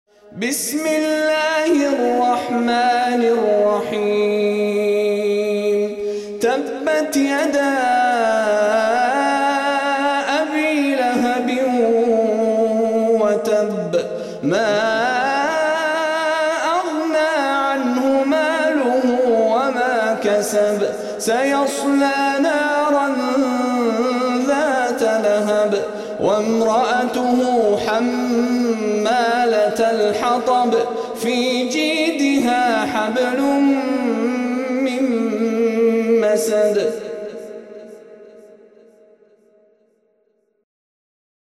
منتخب تلاوتهای شیخ مشاری العفاسی